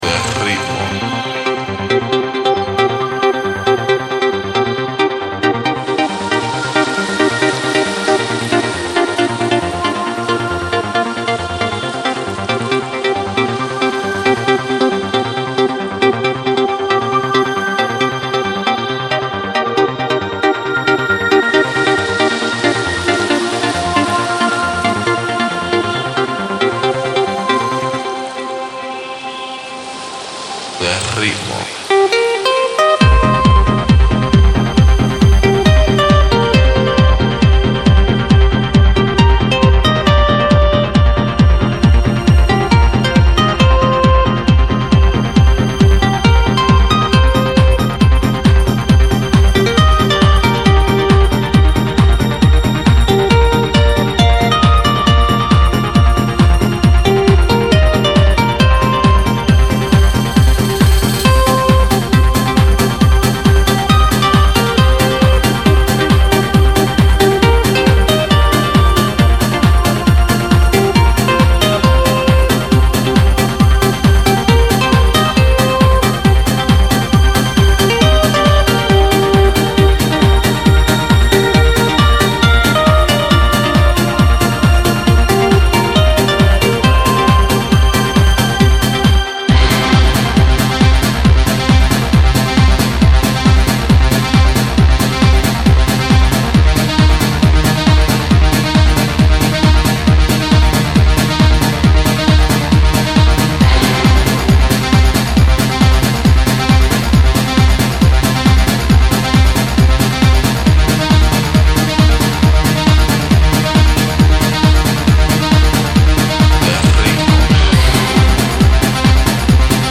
Trance
trancy summer-feeling debut